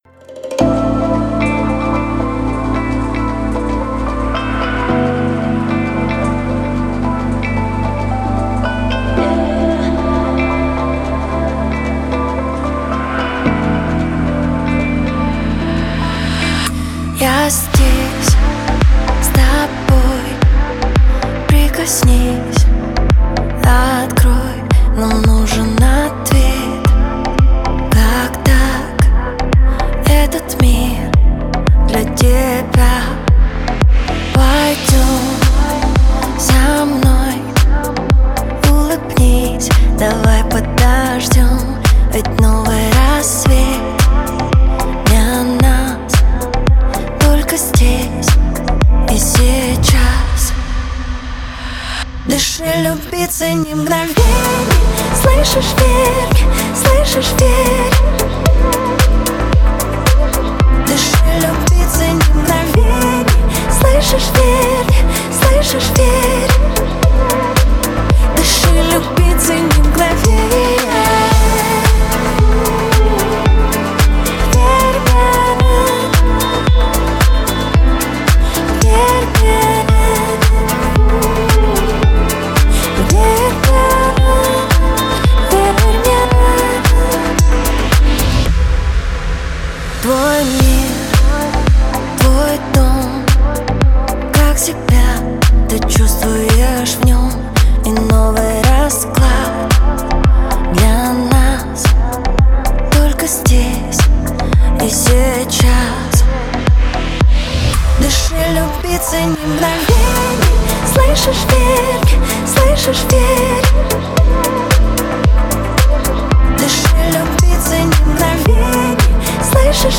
это яркий поп-трек с вдохновляющим настроением.